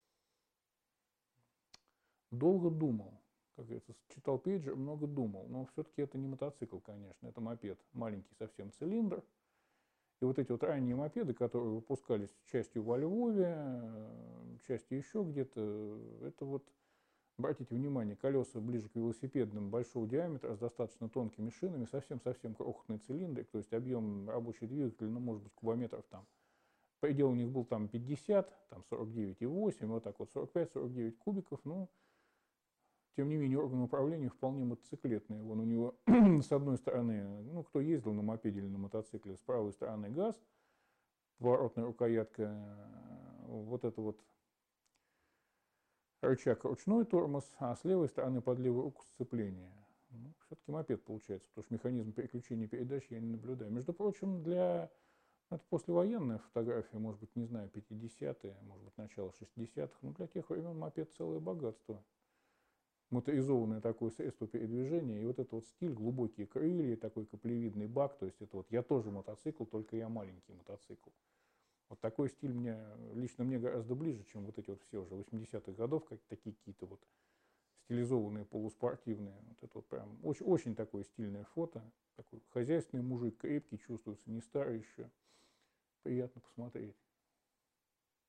3. «Сеансы связи» в Доме Белявского